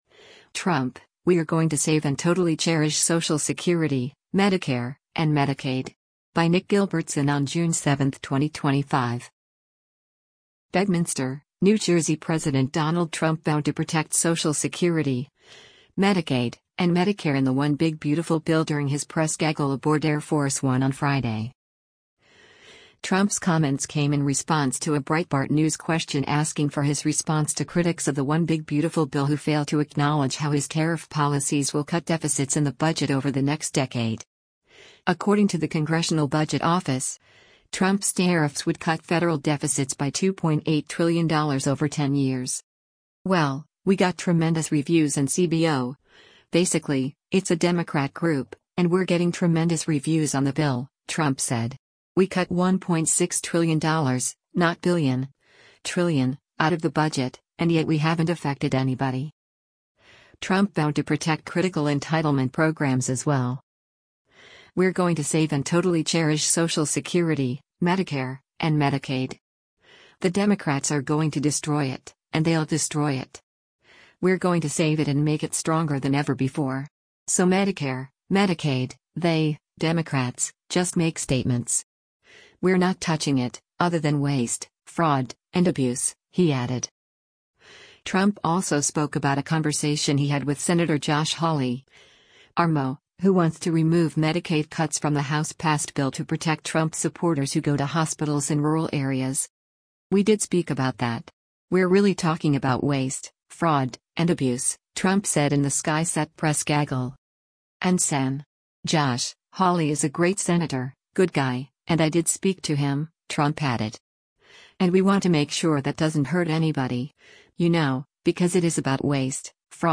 BEDMINSTER, New Jersey–President Donald Trump vowed to protect Social Security, Medicaid, and Medicare in the “One Big Beautiful Bill” during his press gaggle aboard Air Force One on Friday.